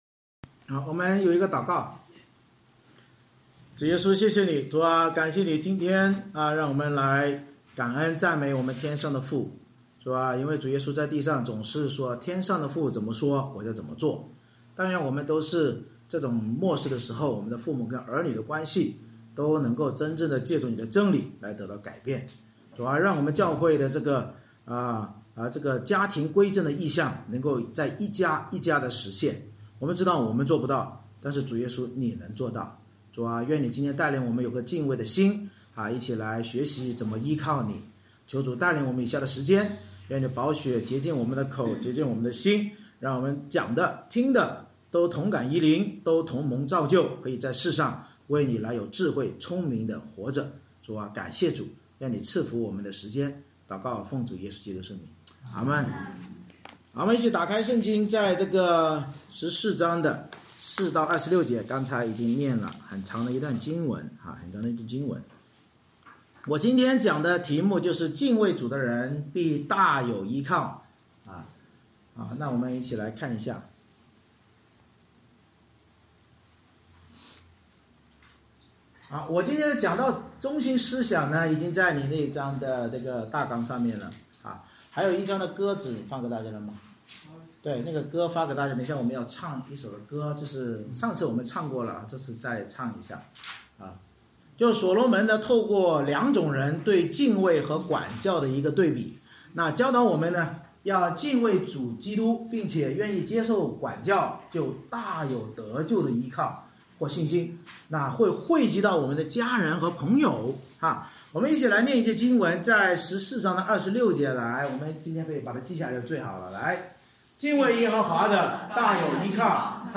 箴言14:4-26 Service Type: 主日崇拜 所罗门箴言通过两种人对敬畏与管教的对比，教导我们要敬畏主耶穌基督并愿接受管教就必大有得救的依靠或信心并惠及家人及朋友。